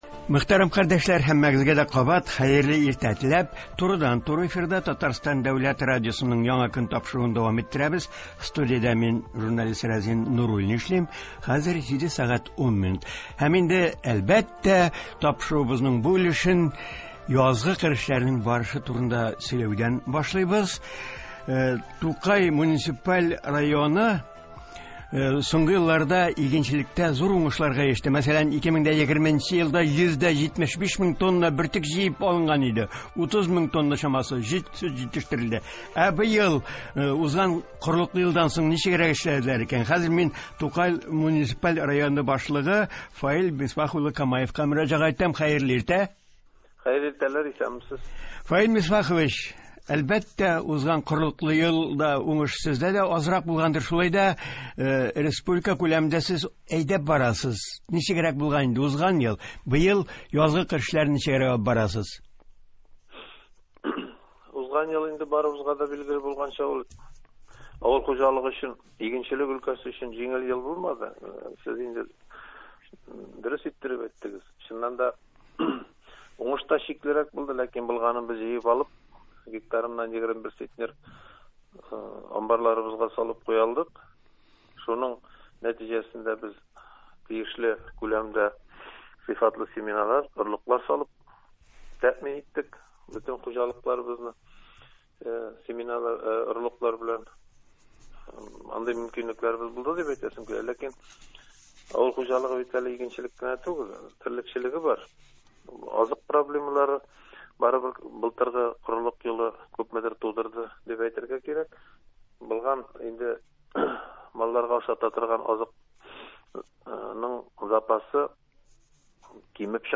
Республикабызда язгы кыр эшләре дәвам итә. Тукай муниципаль районы башлыгы Фаил Камаев телефон элемтәсендә бүгенге вәзгыять турында сөйли.